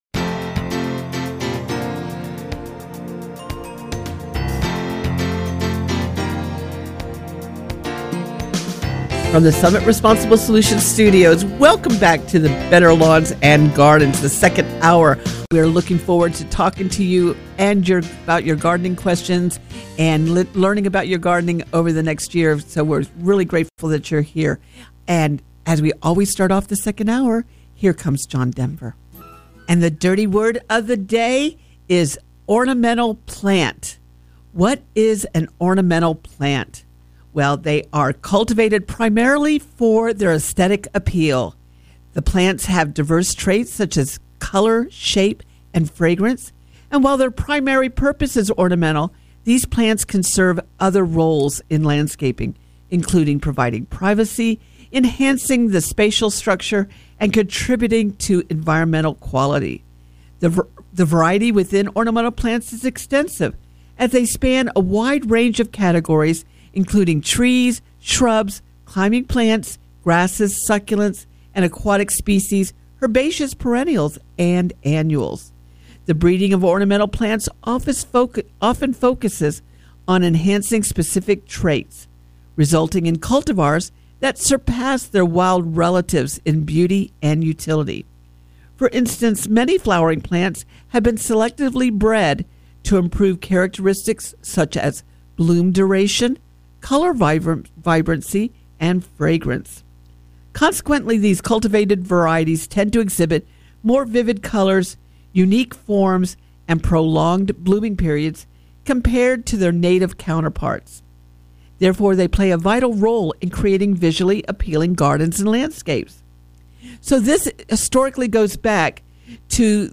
1 S2 Ep336: Better Lawns and Gardens - Hour 2 Winter Gardening Questions January 4, 2025 44:01 Play Pause 1d ago 44:01 Play Pause Daha Sonra Çal Daha Sonra Çal Listeler Beğen Beğenildi 44:01 Better Lawns and Gardens Hour 2 – Coming to you from the Summit Responsible Solutions Studios, Happy New Year!